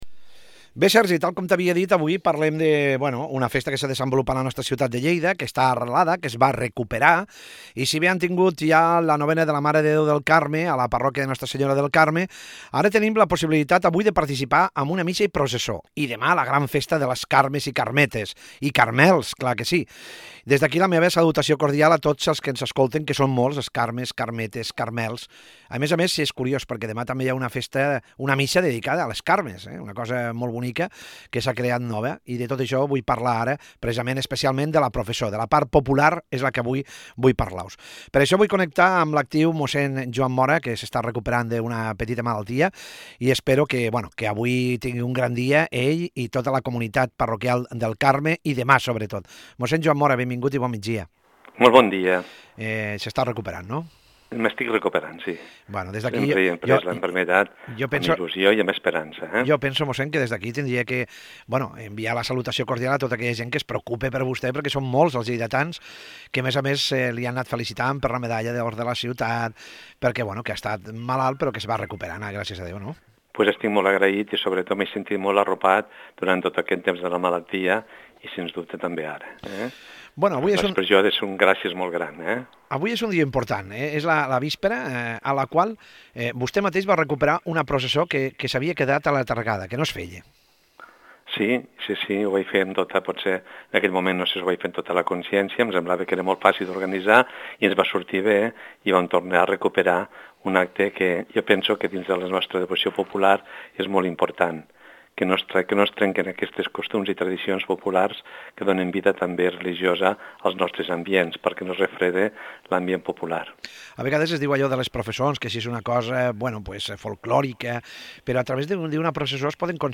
PROCESSÓ I FESTA DEL CARME: ENTREVISTA